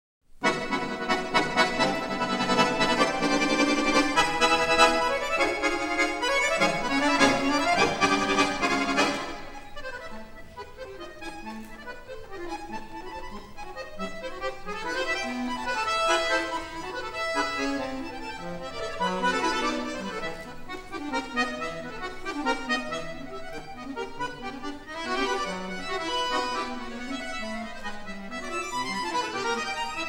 eTrack Music from the 2009 Coupe Mondiale Finale Concert